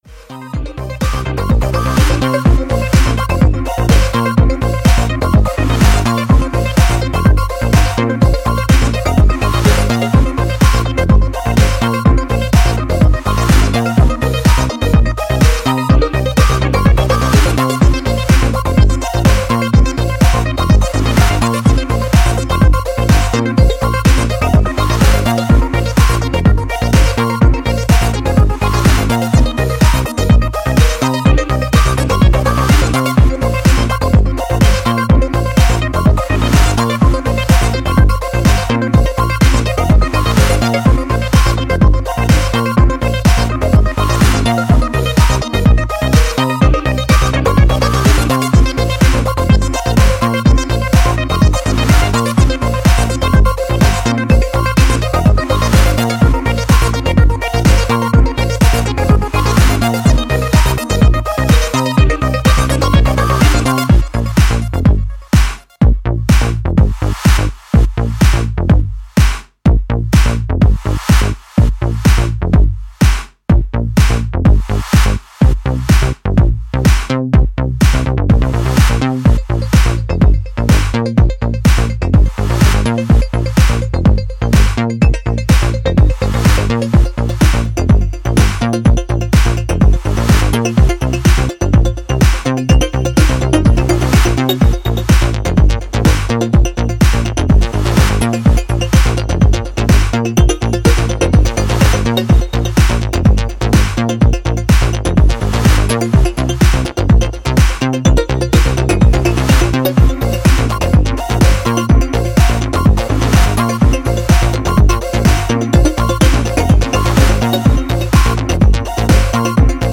ギャラクティックに上昇する予兆を孕む